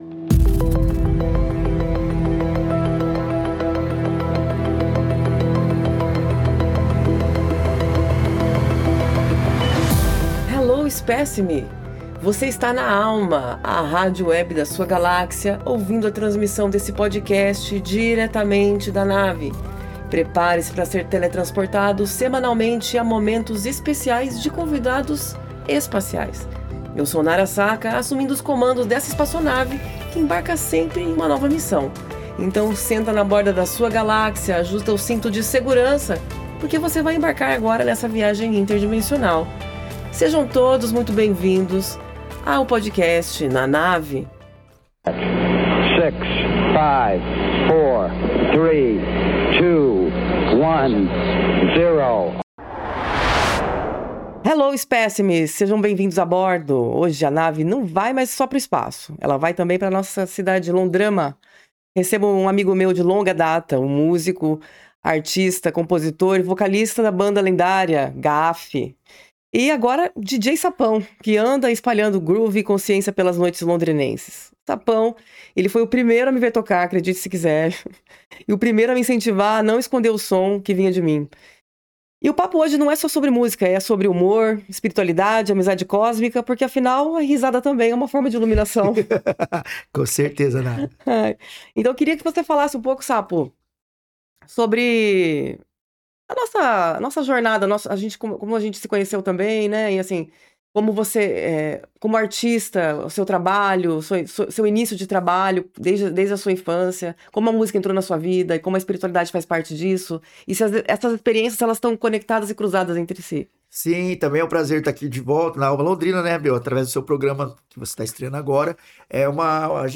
Hoje, o papo é entre amigos que acreditam que humor e espiritualidade andam de mãos dadas…e que a risada também pode ser um portal.